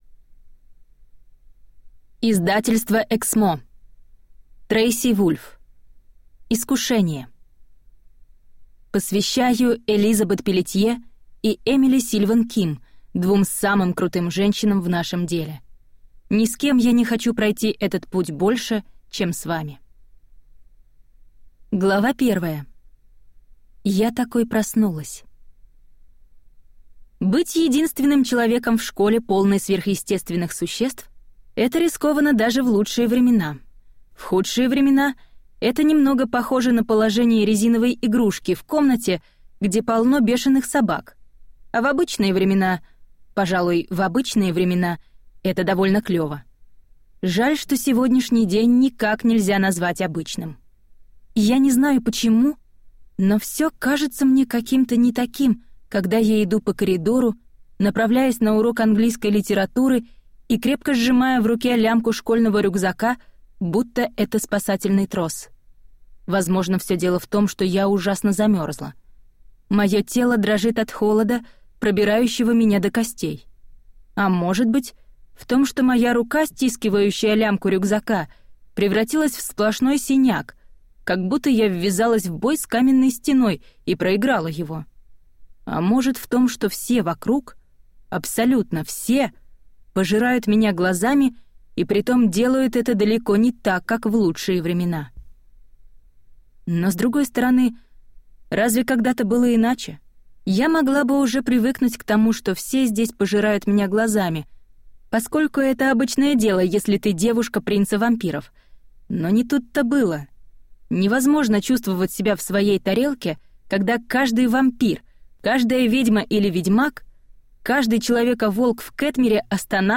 Аудиокнига Искушение | Библиотека аудиокниг